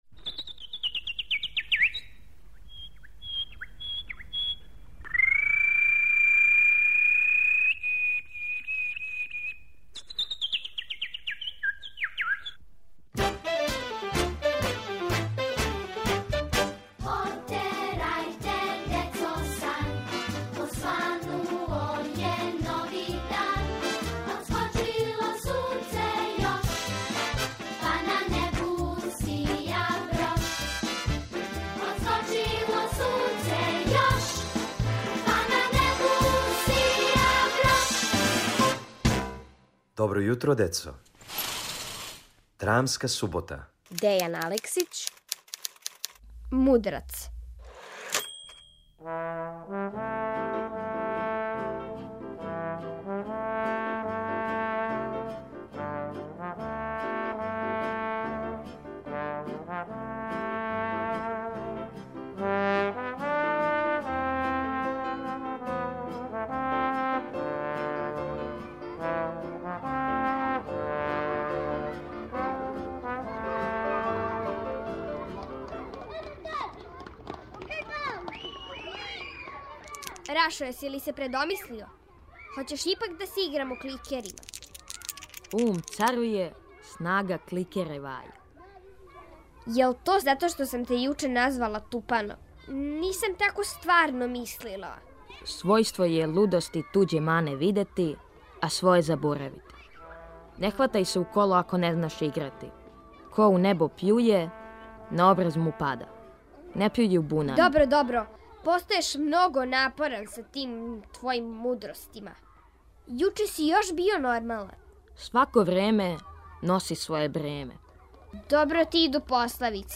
Зашто је један дечак одлучио да говори само у пословицама, сазнајте у краткој драми Дејана Алексића.